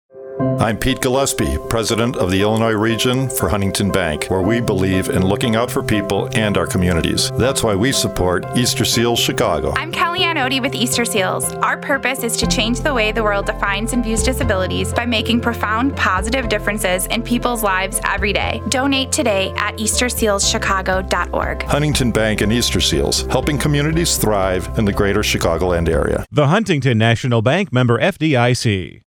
• Easterseals Serving Chicagoland and Rockford — Listen to a 30 second radio ad spot that the affiliate recorded with Huntington Bank in Chicago.